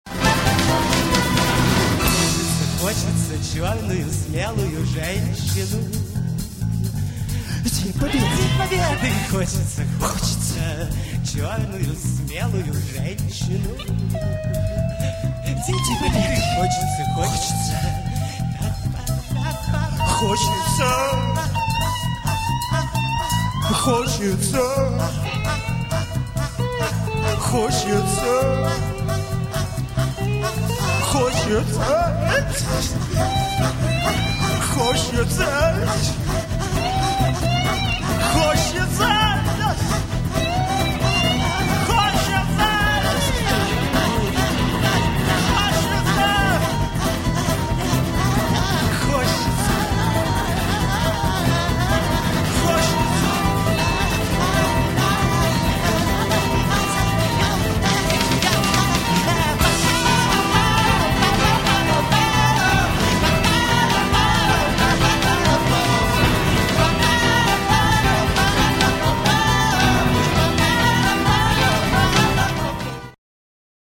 Концерт на Шаболовке (1993)
AUDIO, stereo